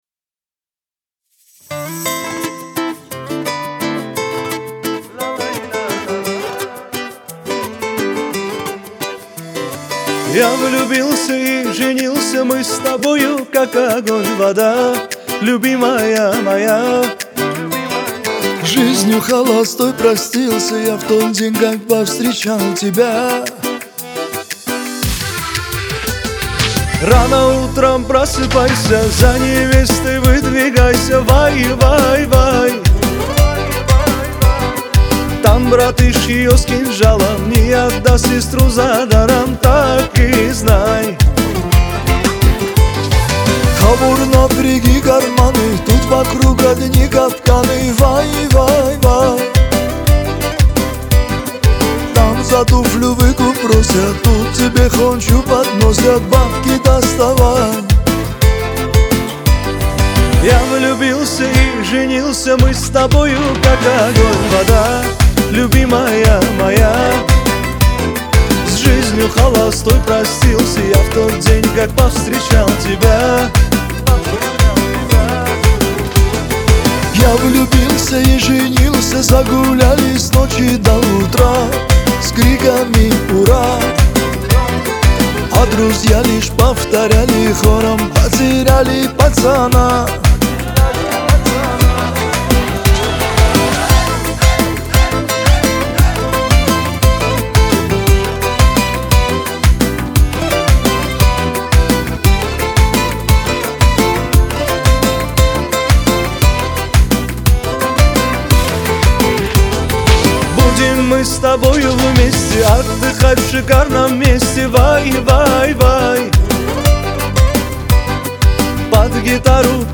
Лирика
Кавказ поп , дуэт